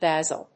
音節ba・sil 発音記号・読み方
/bˈæzl(米国英語)/